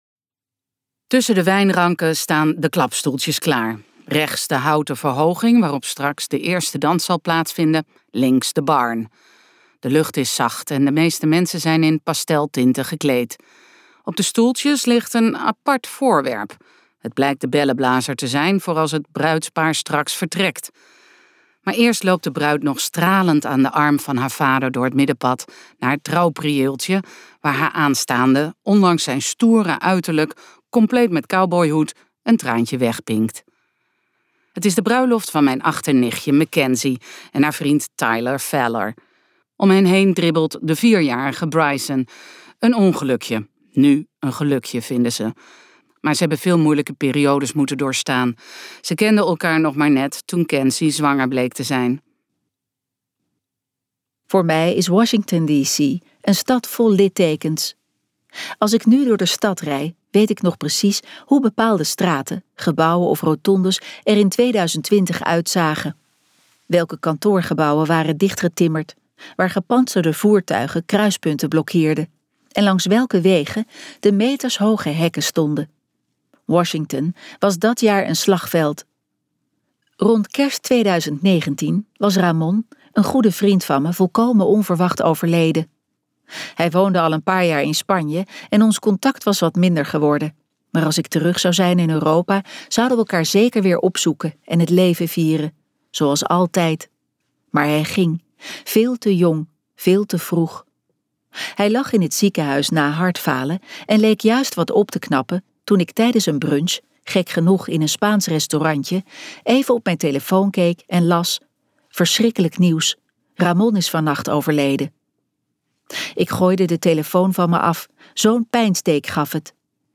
Amerika amerika luisterboek | Ambo|Anthos Uitgevers